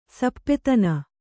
♪ sappetana